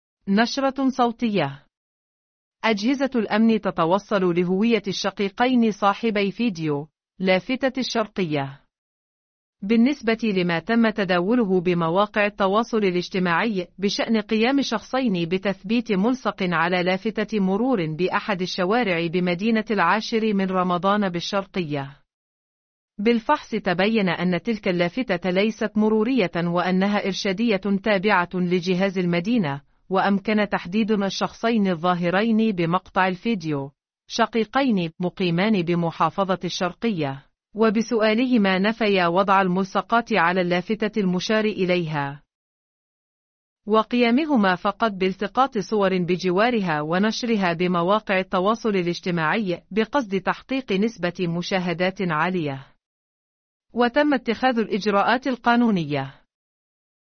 نشرة صوتية..